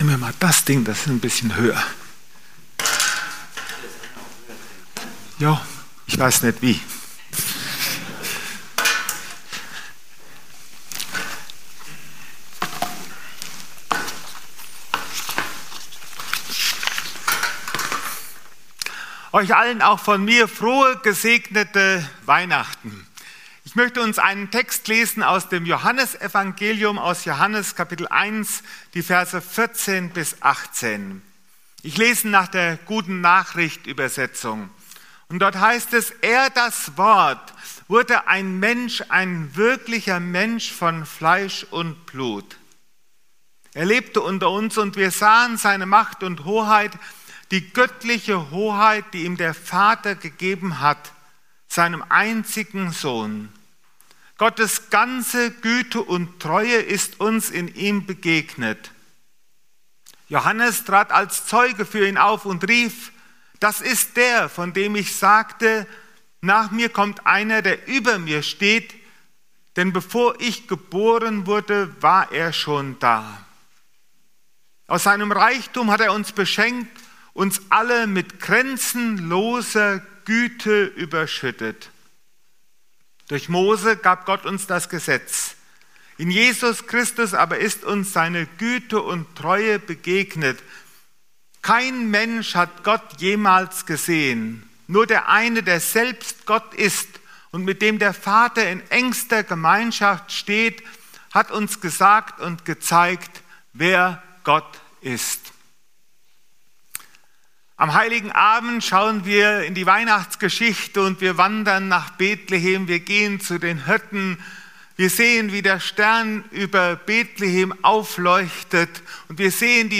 25.12.2025 Weihnachten ~ Predigten - FeG Steinbach Podcast